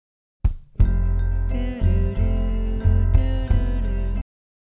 unused vocal melody
(our clip cuts off as abruptly as Brian stops singing!)